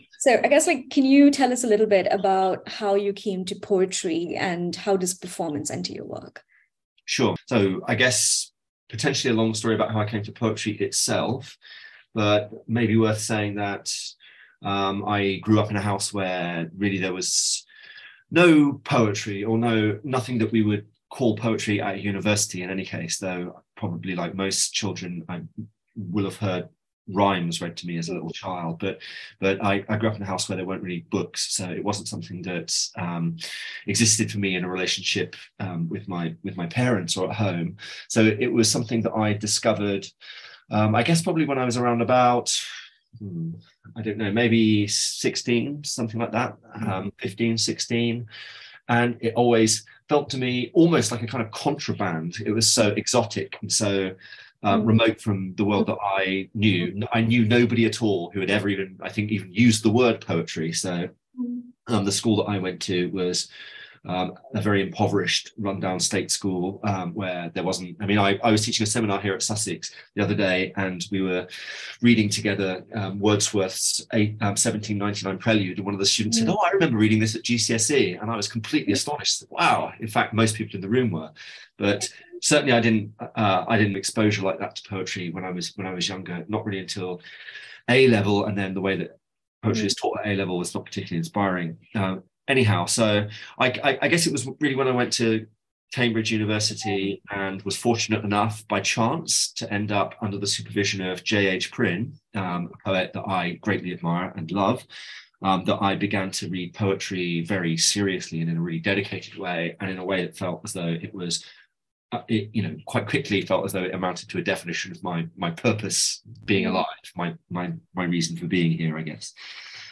Interview Recordings